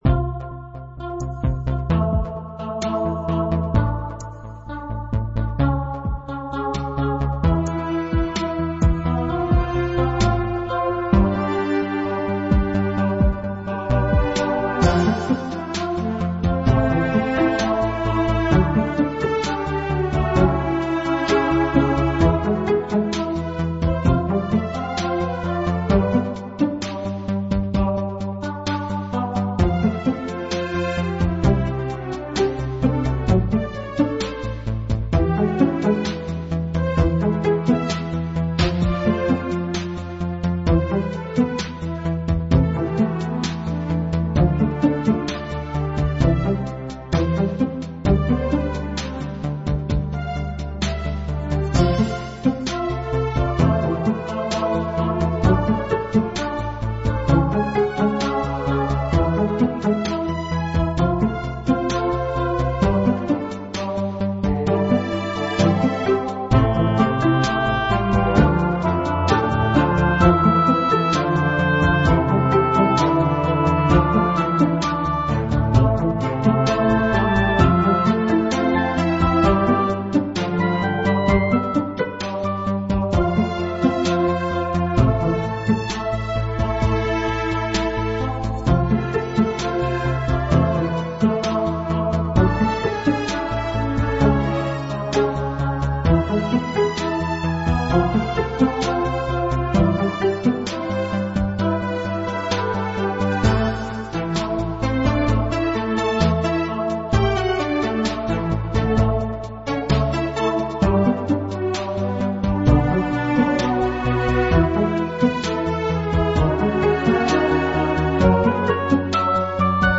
Dramatic Electro Soundtrack music with Orchestra lead